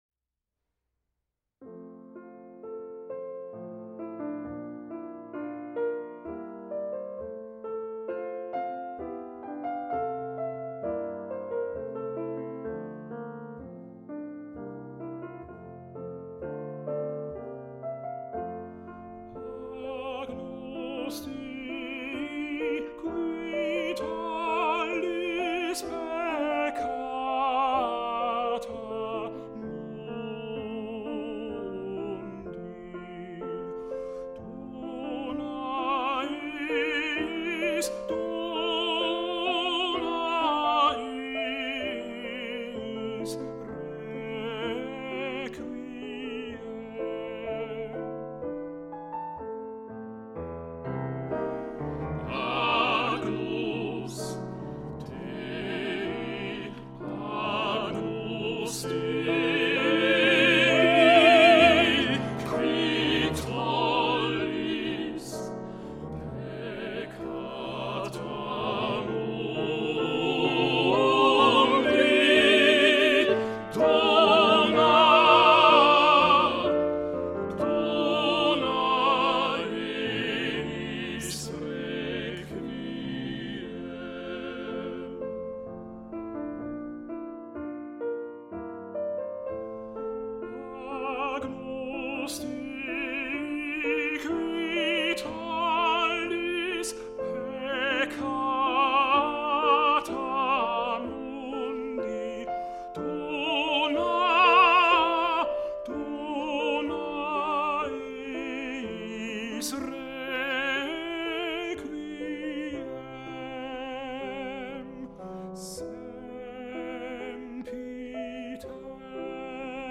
agnus dei basse 1